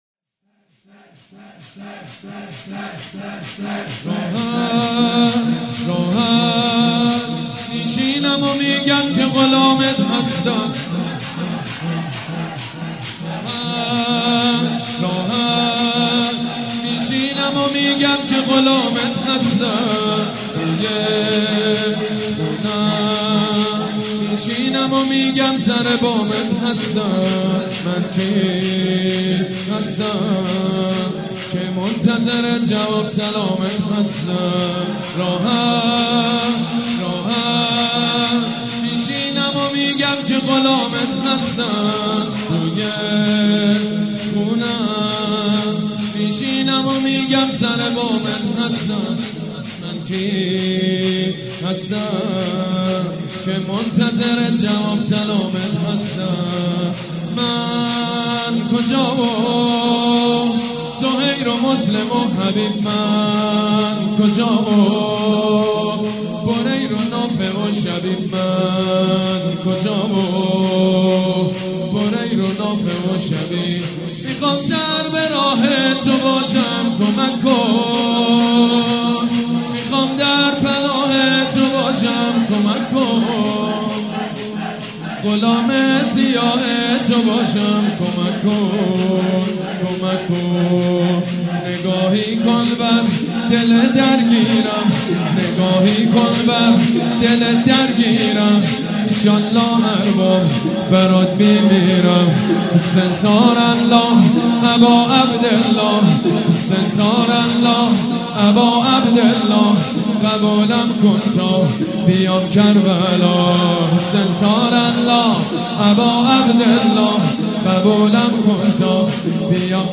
«شهادت امام جواد 1394» شور: راحت می شینم و میگم که غلامت هستم